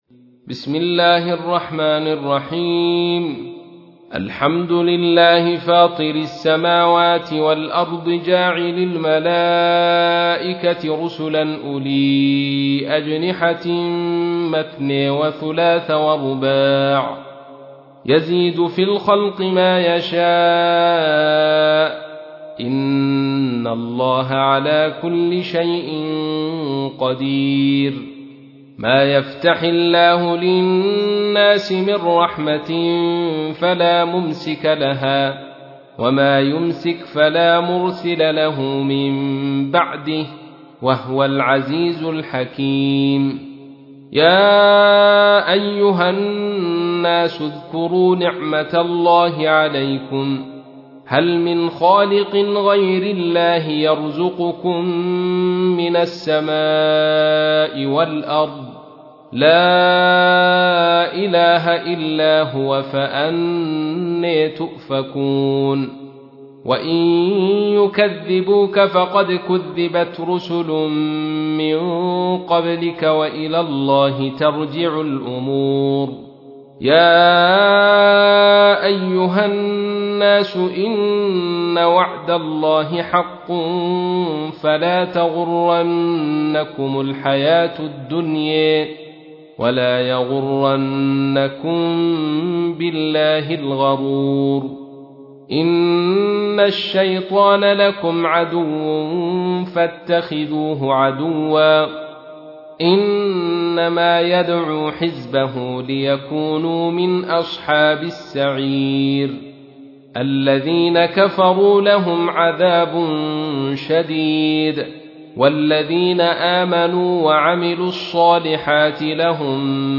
تحميل : 35. سورة فاطر / القارئ عبد الرشيد صوفي / القرآن الكريم / موقع يا حسين